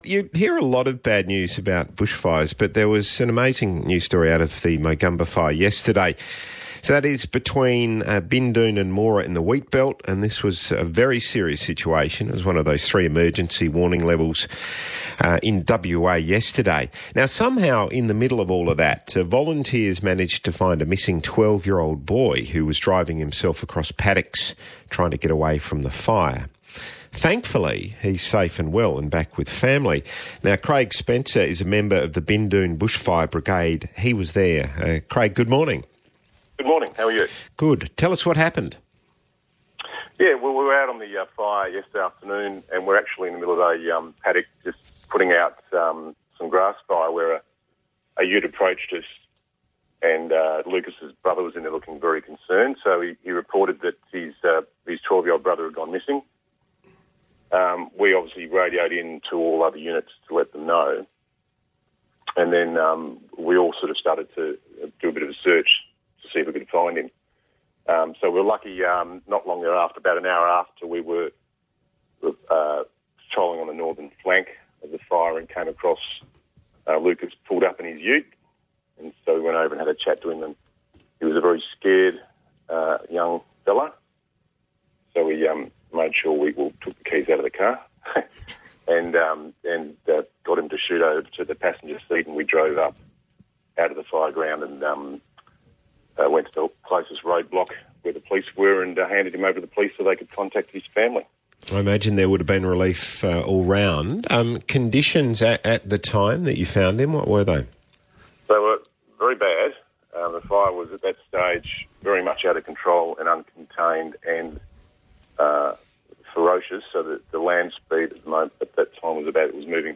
Broadcast: